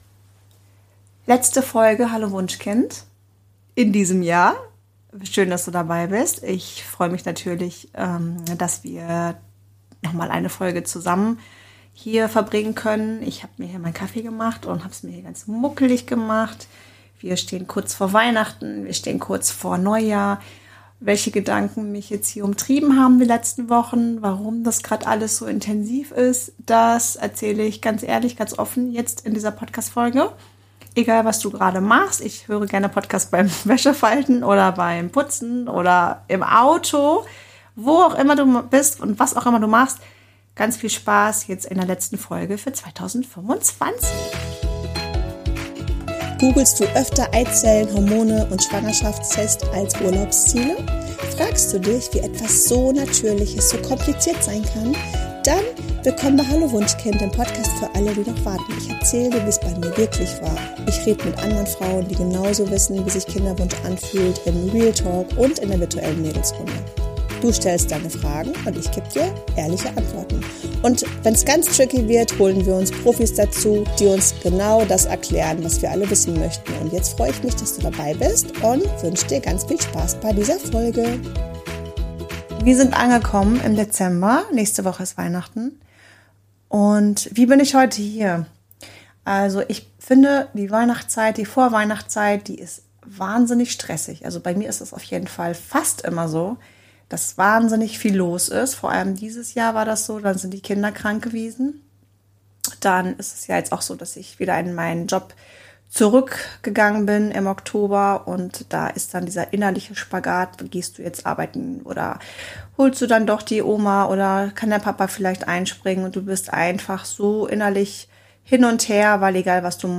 In dieser Solo-Folge spreche ich offen darüber, wie ich mit Herausforderungen umgehe, was dieses Jahr wirklich mit mir gemacht hat und wie ich ins neue Jahr starte – ohne Schönreden.